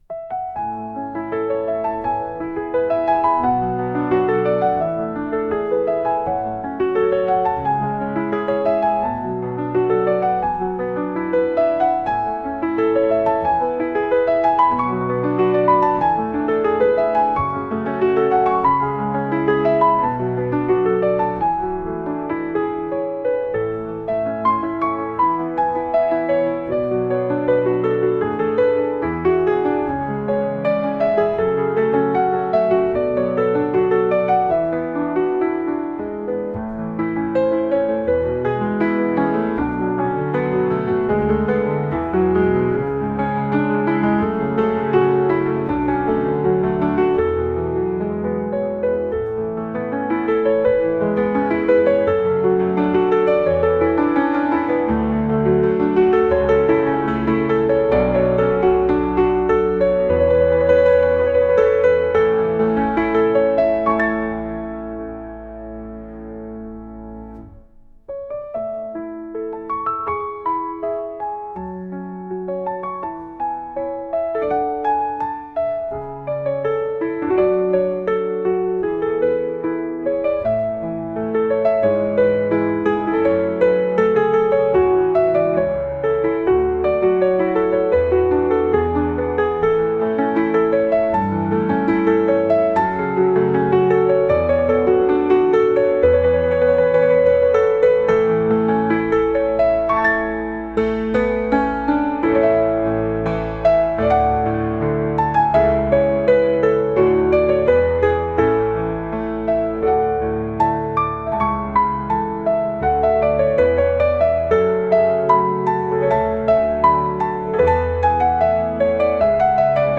ノスタルジーを感じるようなピアノ音楽です。